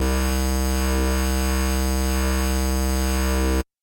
Step 1 – Oscillators
Here is an example of the two oscillators together, played at C2 with a little manual movement on the WT position for osc1.